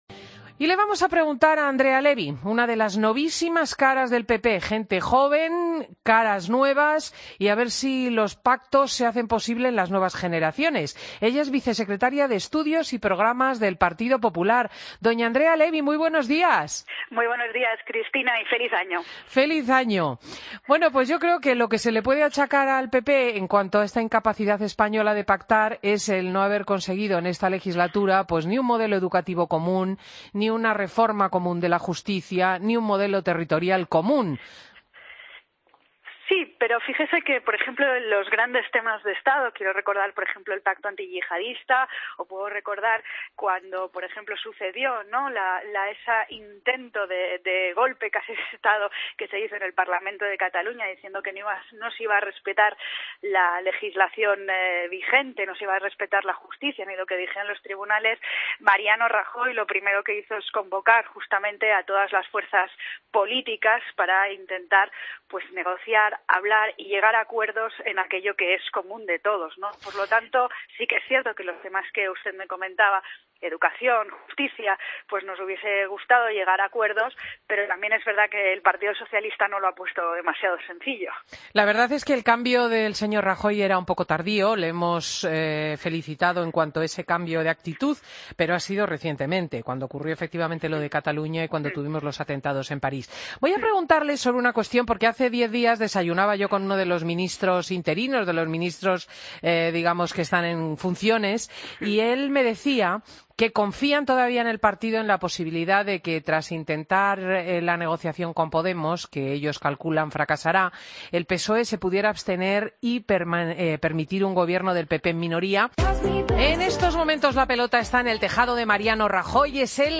Escucha la entrevista a Andrea Levy en Fin de Semana Cope